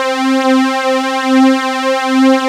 SAWTEETH.wav